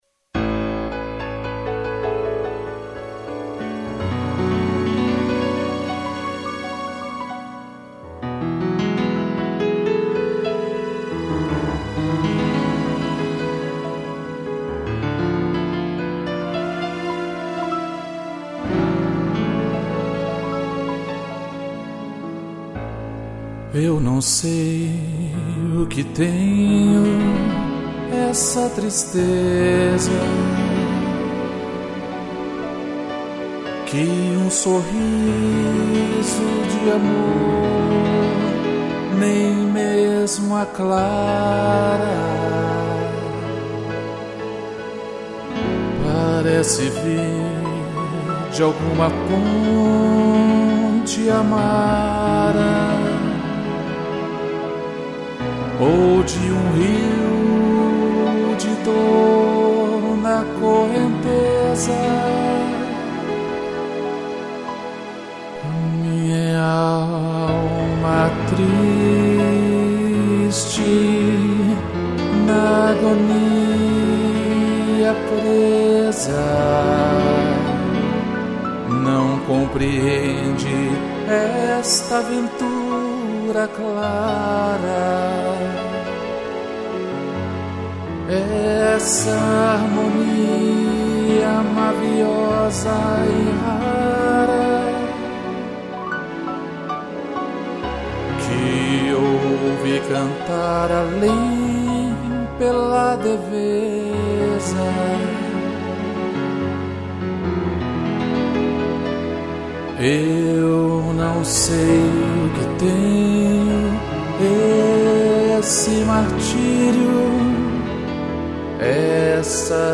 piano e strings